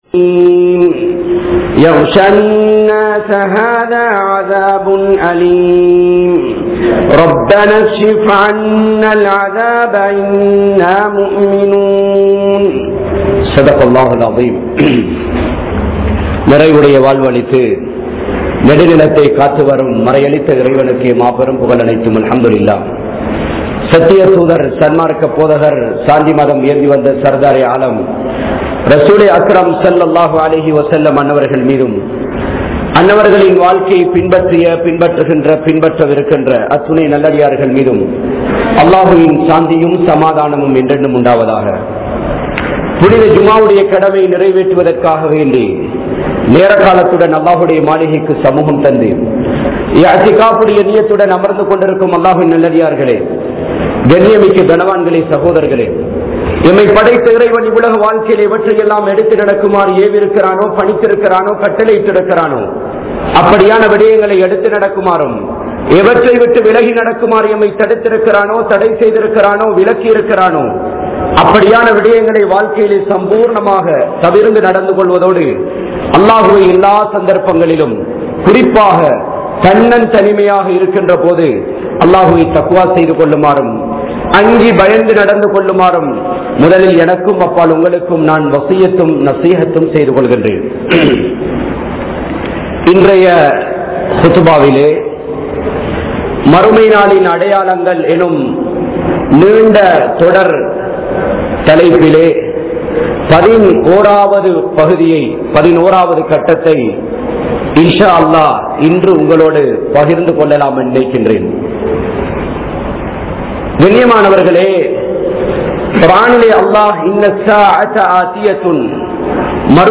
Qiyaamath Naalin Adaiyaalangal(Part11) (கியாமத் நாளின் அடையாளங்கள்) | Audio Bayans | All Ceylon Muslim Youth Community | Addalaichenai
Saliheen Jumua Masjidh